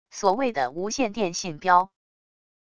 所谓的无线电信标wav音频